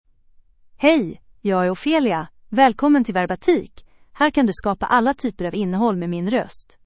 OpheliaFemale Swedish AI voice
Ophelia is a female AI voice for Swedish (Sweden).
Voice sample
Listen to Ophelia's female Swedish voice.
Female
Ophelia delivers clear pronunciation with authentic Sweden Swedish intonation, making your content sound professionally produced.